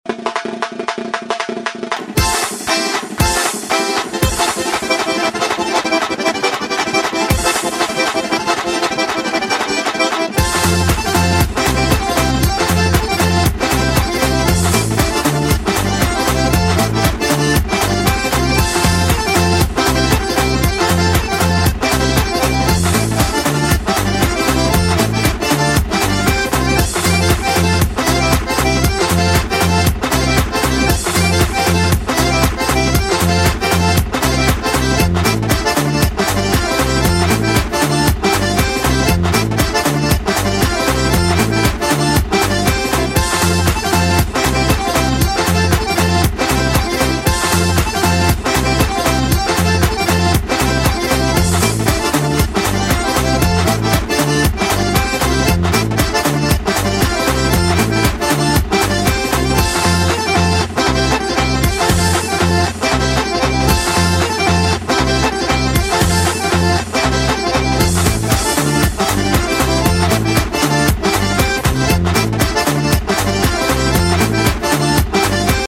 • Качество: 256, Stereo
ритмичные
громкие
без слов
кавказские
лезгинка
национальные